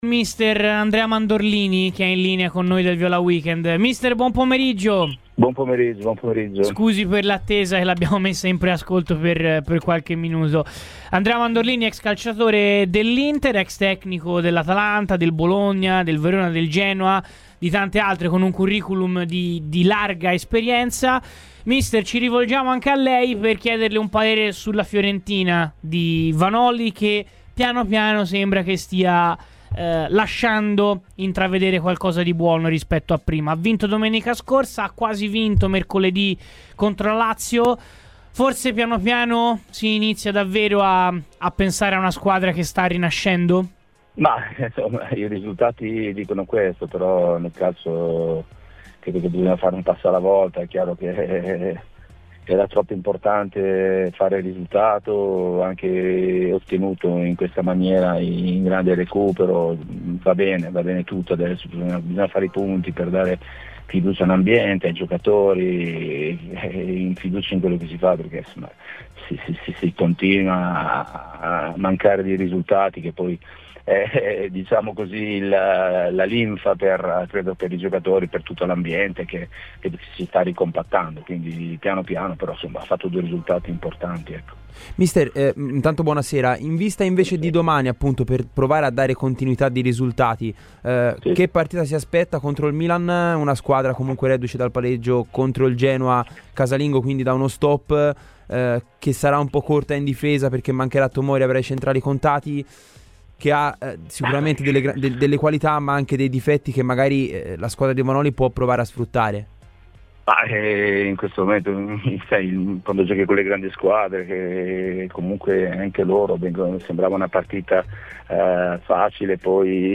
Il tecnico Andrea Mandorlini è intervenuto a Radio FirenzeViola durante "Viola Weekend" per parlare della ripresa della Fiorentina: "Nel calcio bisogna fare un passo alla volta, ma era importante a fare risultato e fare punti, per dare fiducia all'ambiente ma anche ai giocatori perché se non ci sono quelli manca la linfa e dunque la vittoria e il pareggio sono stati risultati importanti".